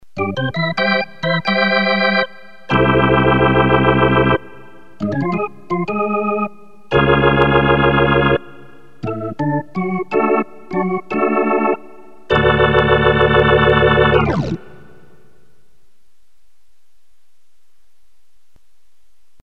Organ Charge
Category: Sports   Right: Personal
Tags: Princeton Hockey Baker Rink